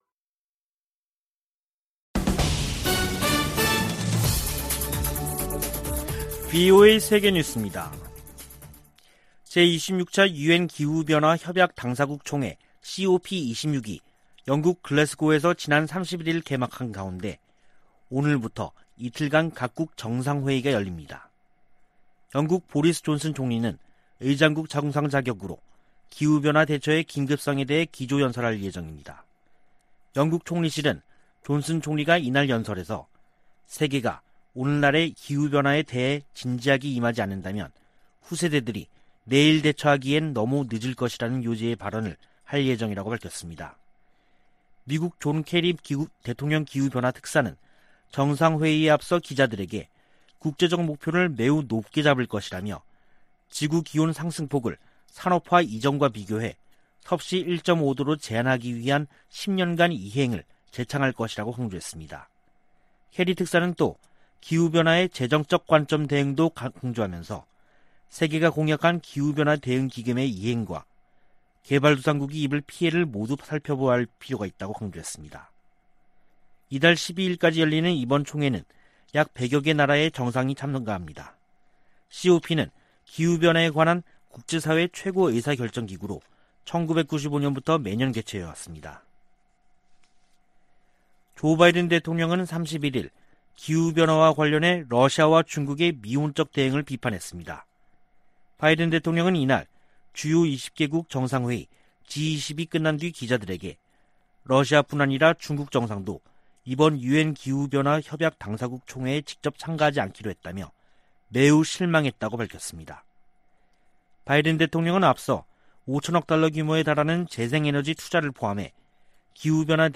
VOA 한국어 간판 뉴스 프로그램 '뉴스 투데이', 2021년 11월 1일 2부 방송입니다. 미 국무부가 북한의 대량살상무기(WMD) 확산 방지를 위해 활동하는 단체들에 총 1천200만 달러를 지원하기로 했습니다. 국경 봉쇄로 인한 북한의 식량난이 김정은 위원장의 지도력에 타격을 줄 수 있다고 전문가들이 분석했습니다. 미-중 갈등이 계속되는 가운데 북한과 중국이 전략적 협력을 강조하고 있습니다.